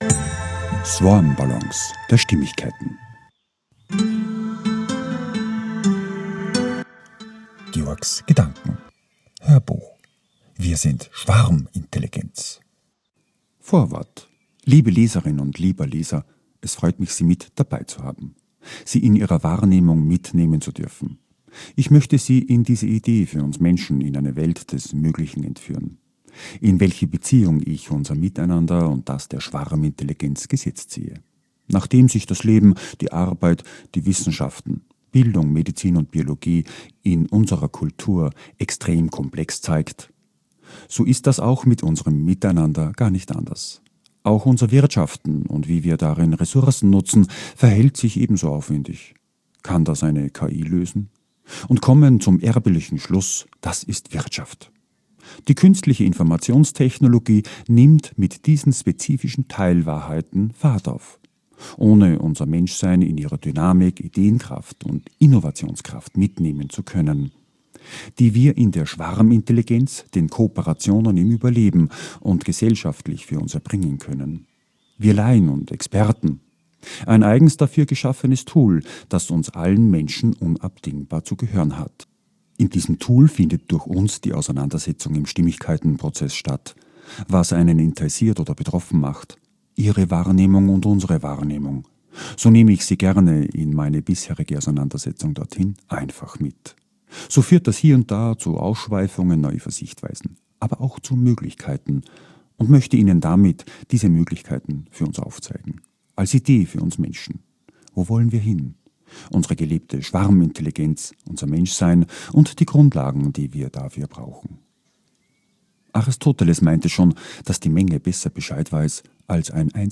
HÖRBUCH - WIR SIND SCHWARMINTELLIGENZ - VORWORT